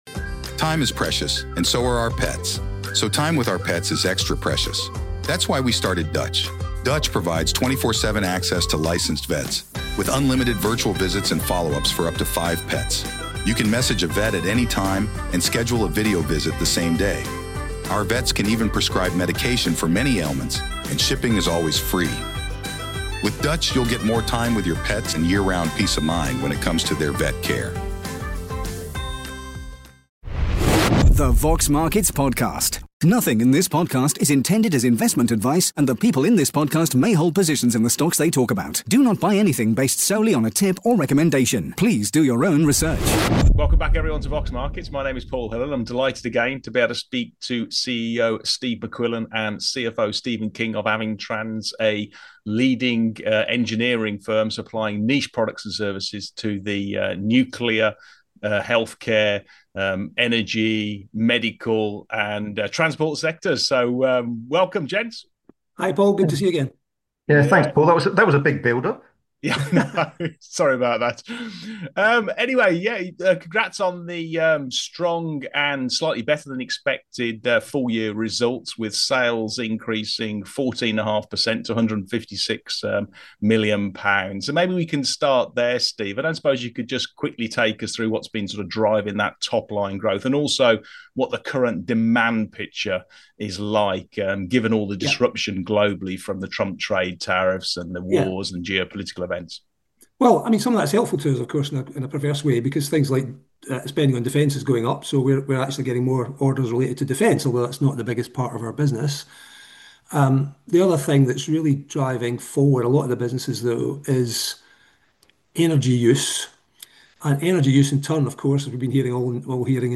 In this positive interview